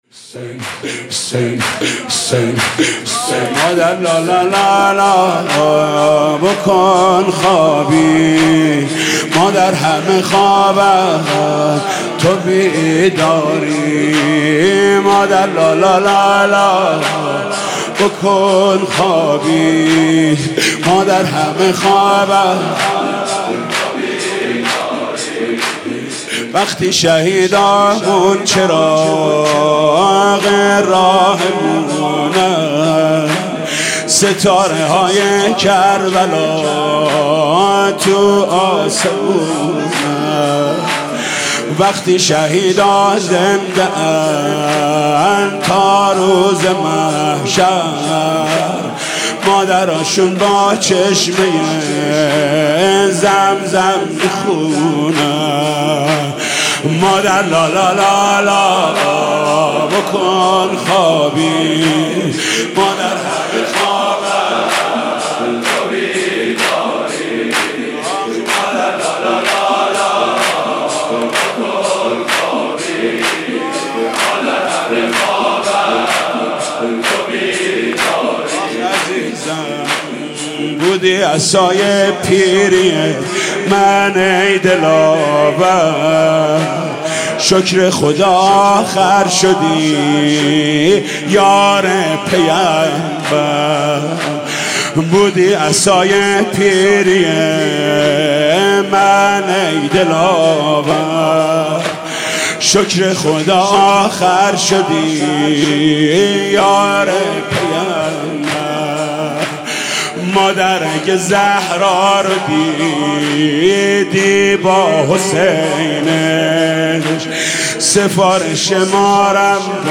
مناسبت : شب هفتم محرم
قالب : زمینه